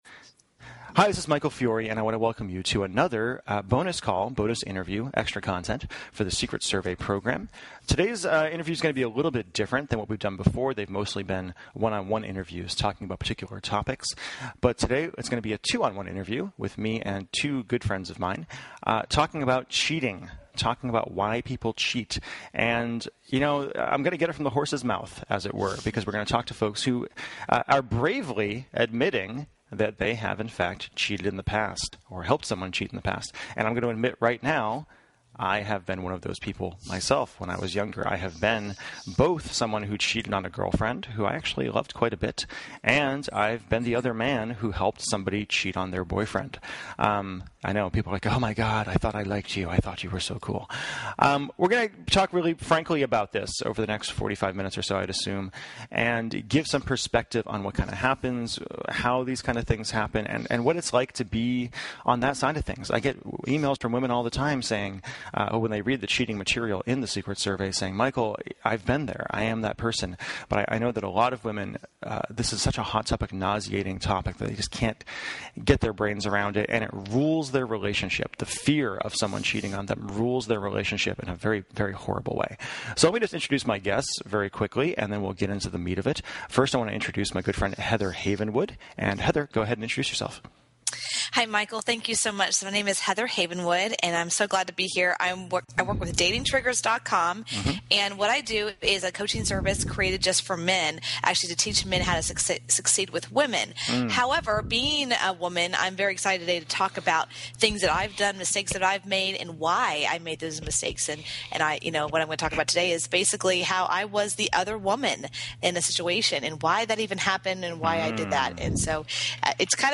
Interview with a Cheater
In this training I have a frank and honest discussion on CHEATING with two folks who have not only been cheated on, but have cheated themselves.
Interview+With+A+Cheater.mp3